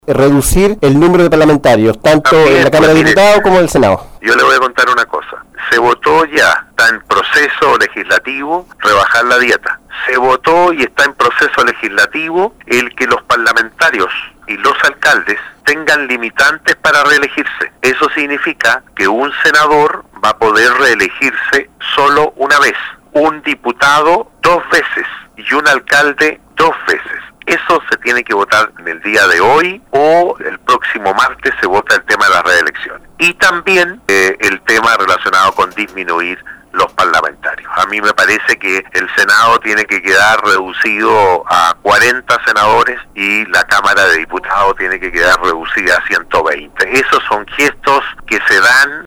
En conversación con Radio Sago, el Senador por la región de Los Lagos, Rabindranath Quinteros, al ser consultado si es que él está o no de acuerdo con reducir el número de parlamentarios tanto de Diputados como Senadores, de manera espontánea y categórica, el Senador del Partido Socialista dijo no estar de acuerdo.